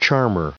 Prononciation du mot charmer en anglais (fichier audio)